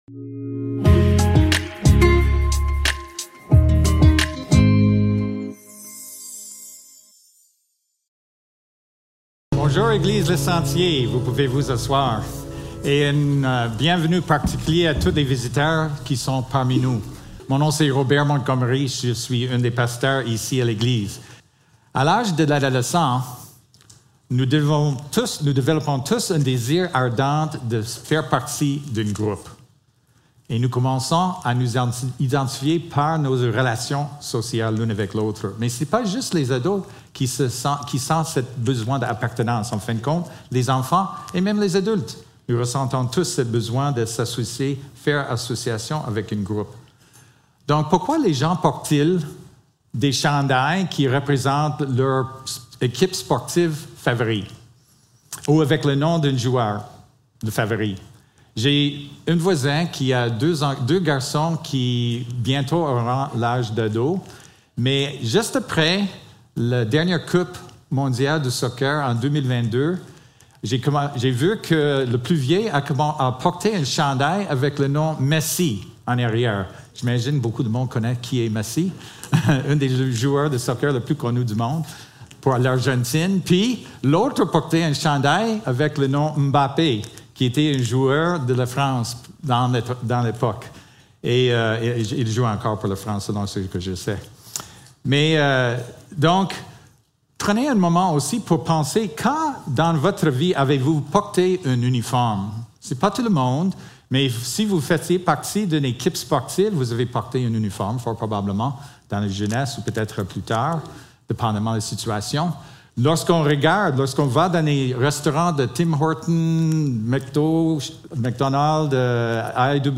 Marc 1.4-11 Service Type: Célébration dimanche matin Description